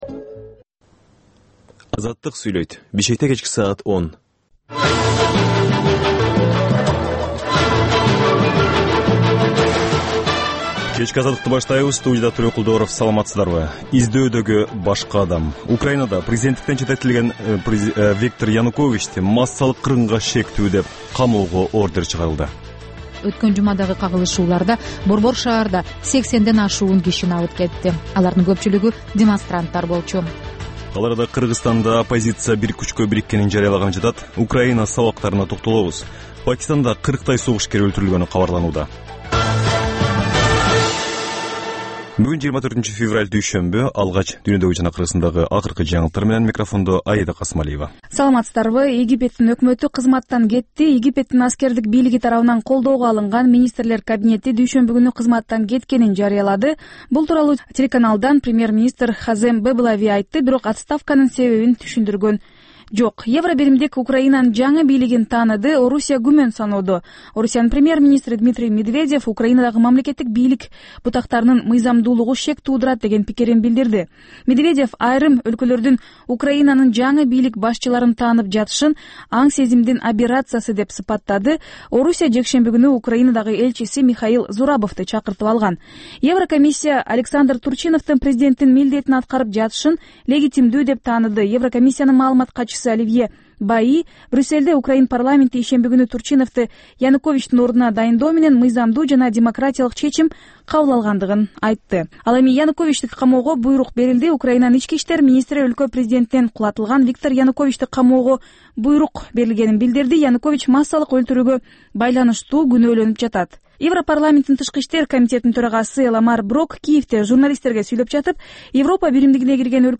Азаттыктын кабарлары